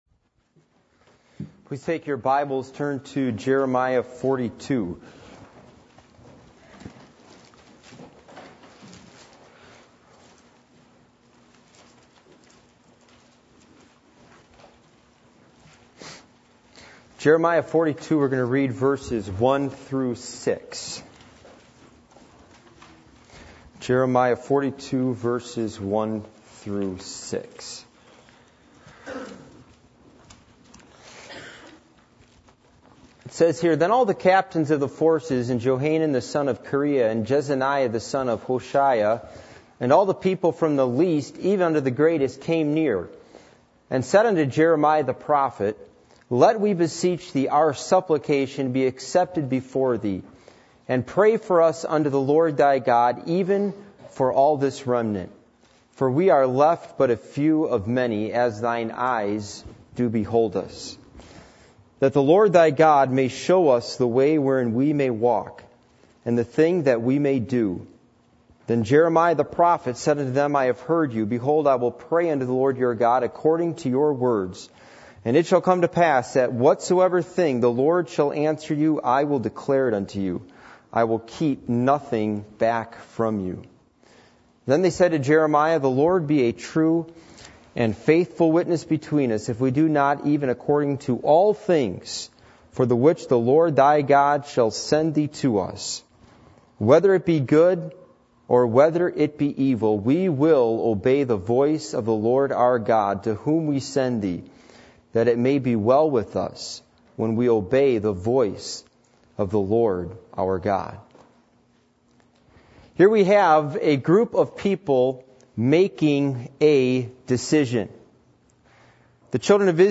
Jeremiah 42:1-6 Service Type: Midweek Meeting %todo_render% « How Are You Going To Finish Your Life?